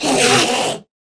scream2.ogg